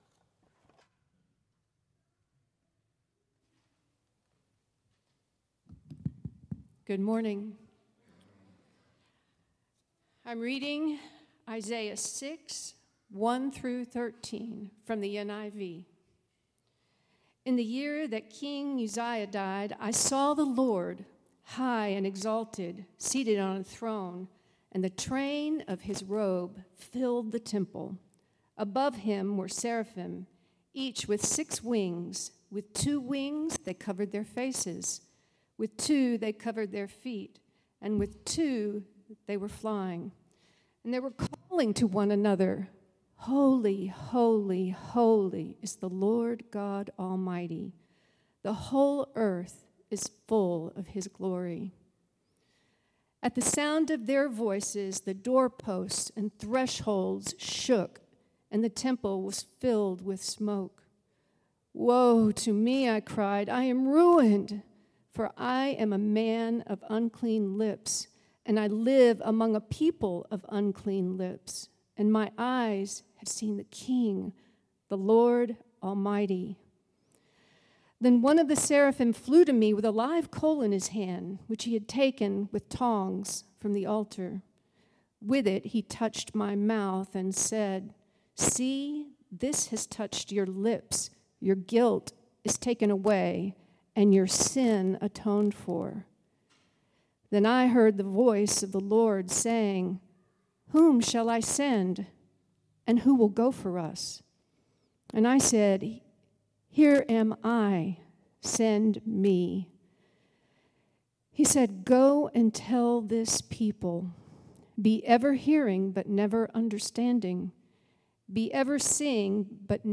Hear expository sermons from the teaching team of Trinity Fellowship Church in Richardson, Texas.